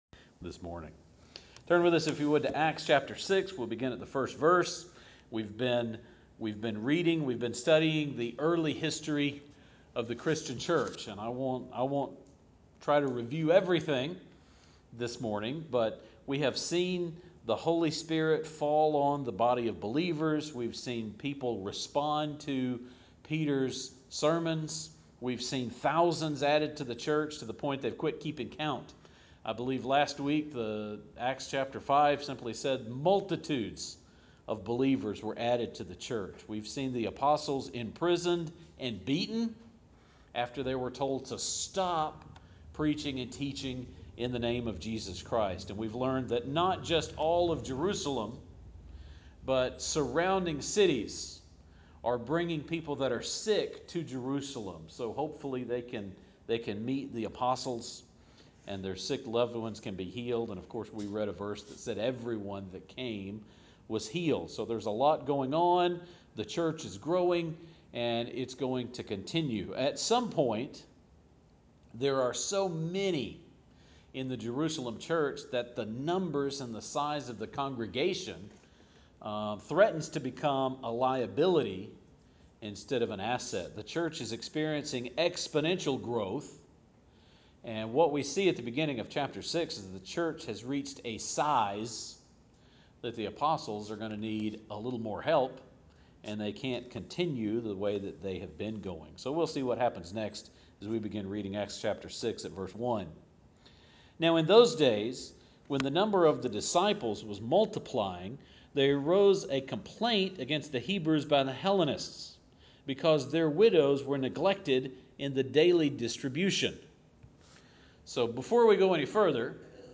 Ministry involves preaching the Word and serving tables. The Apostles recruit some help in Acts 6 and outline the characteristics of who qualifies. We don’t turn to Exodus 18 in this sermon (but we did read it last Wednesday and looked at it again Sunday evening) but you might want to take a look.